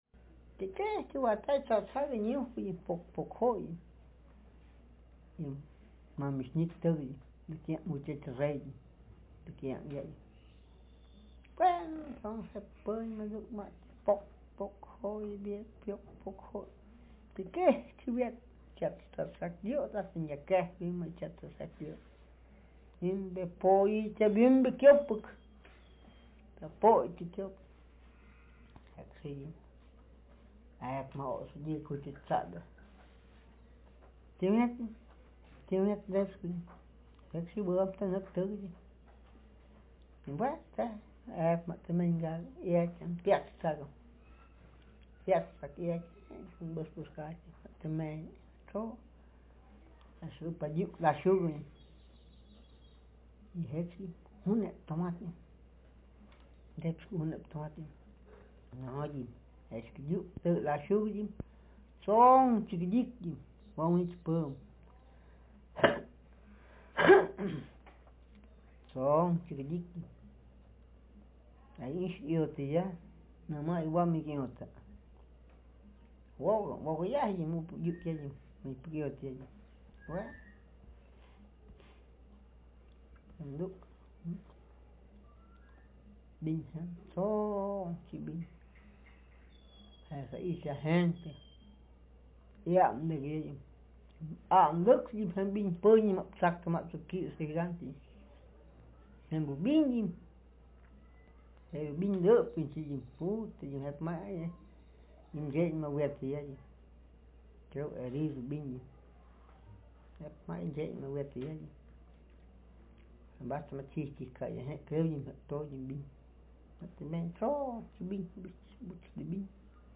Speaker sex m Text genre traditional narrative